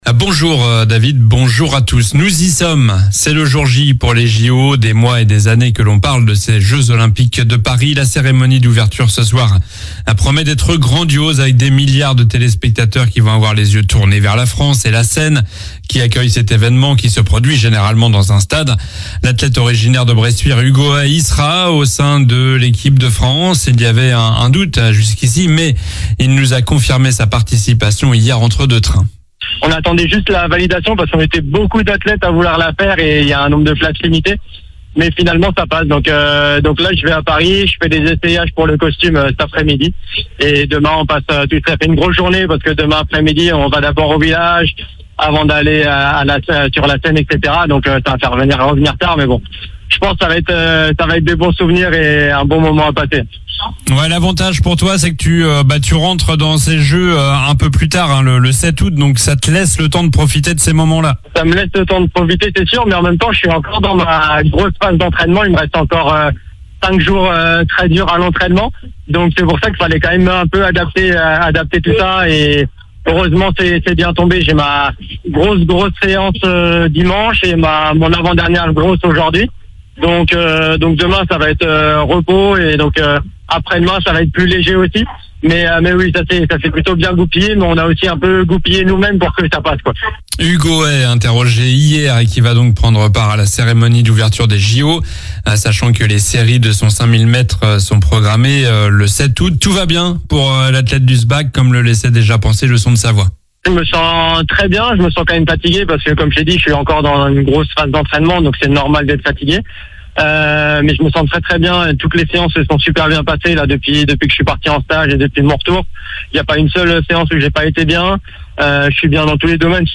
Journal du vendredi 26 juillet (matin)